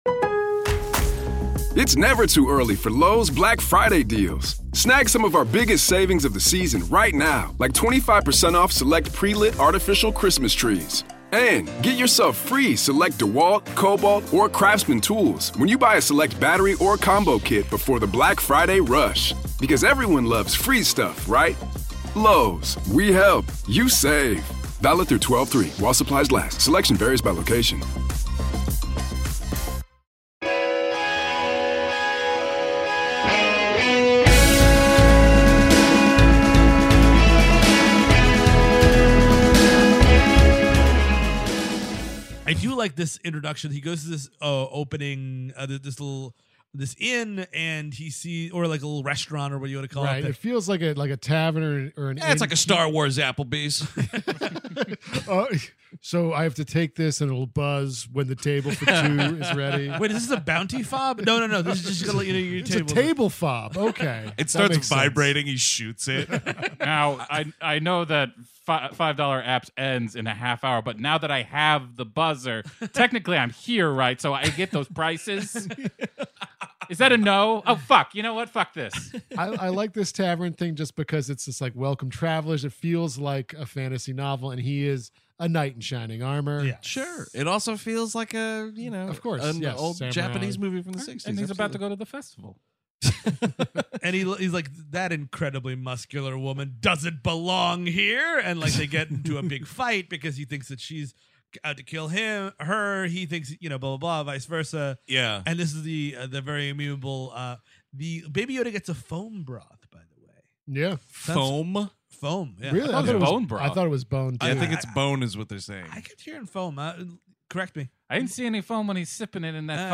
On this week's edition of The Mandalorian Half-Hour, the gang is together for a rare in-studio convo, chatting about the show's fourth installment, "Chapter 4: Sanctuary"!